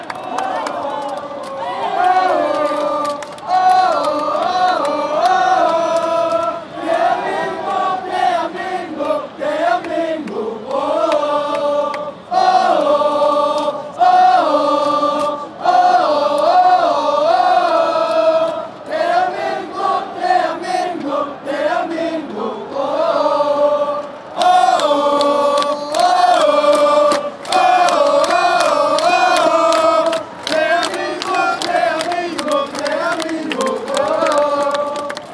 A S. Korea soccer chant.
Korean Folk Song